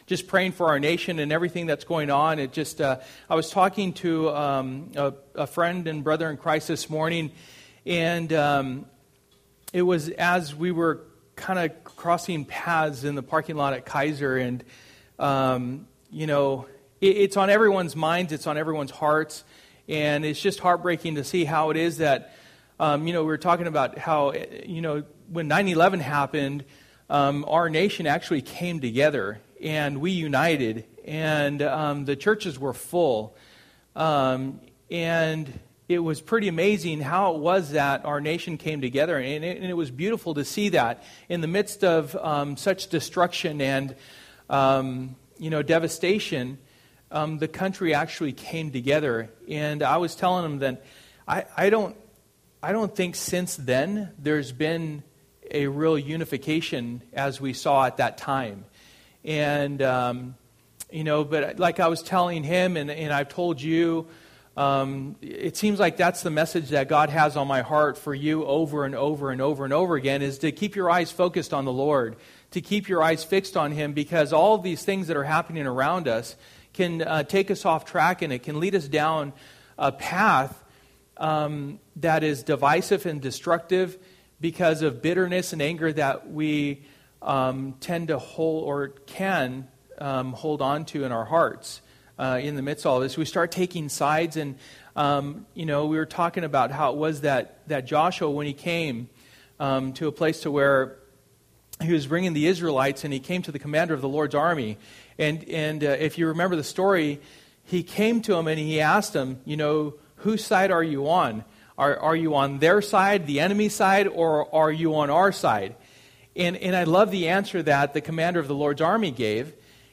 Through the Bible Service: Wednesday Night %todo_render% « Our Readiness is Determined by our Actions No Superstars